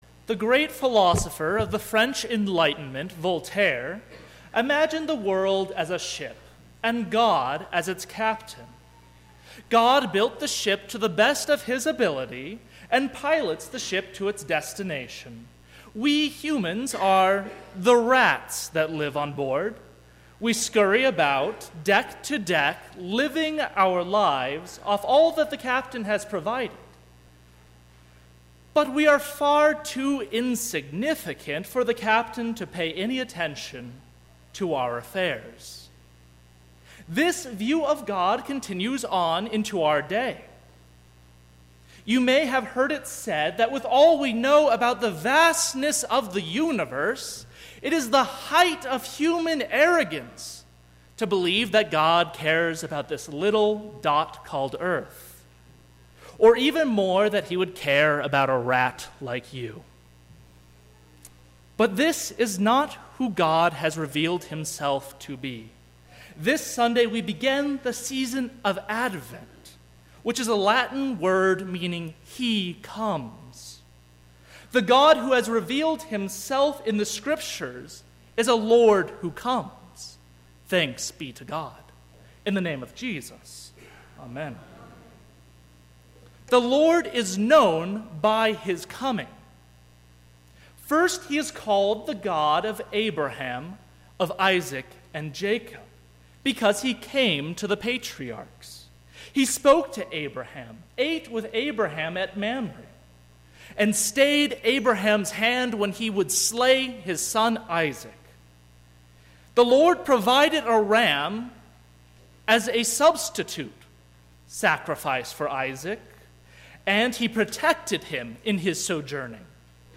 Sermon – 12/3/2017
Sermon_Dec3_2017.mp3